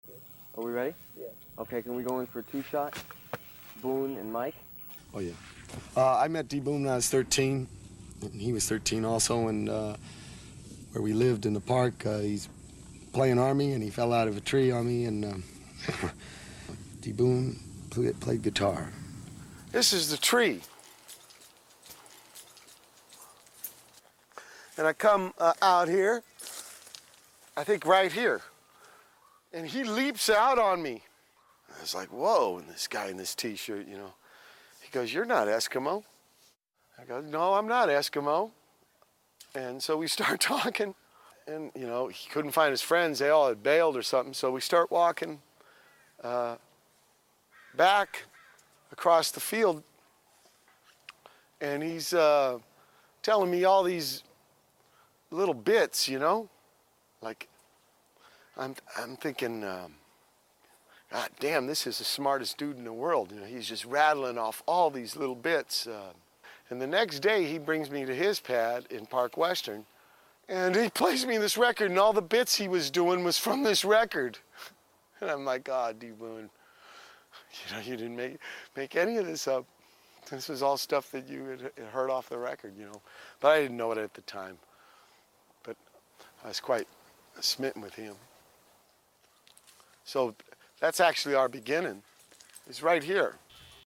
It’s San PEE dro, not PAY dro sound effects free download